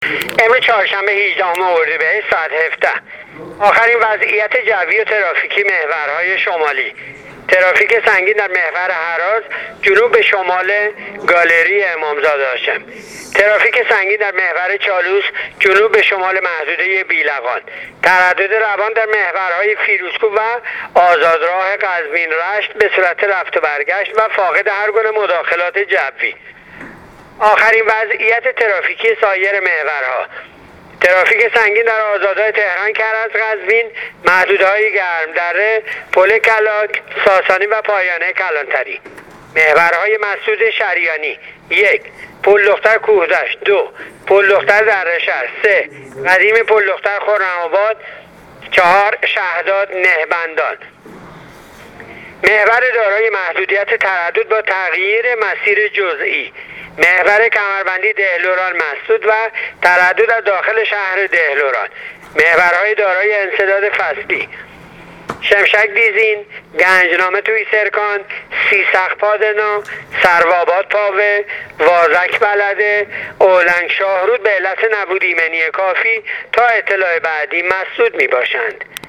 گزارش رادیو اینترنتی وزارت راه و شهرسازی از آخرین وضعیت‌ ترافیکی راه‌های کشور تا ساعت ۱۷هجدهم اردیبهشت/ترافیک سنگین در محور هراز و چالوس و آزادراه قزوین - رشت (مسیر رفت و برگشت)